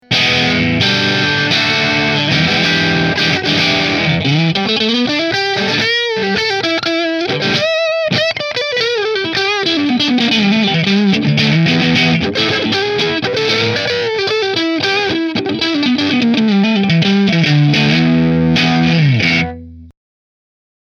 It has the snappiness from the Les Paul Special with it’s P90s, but also has the rounded tone from the semi-hollow design of the Telecaster Deluxe.
• Three Custom Wound P90s
New Orleans Guitars Model-8 Sunburst Neck Middle Through Marshall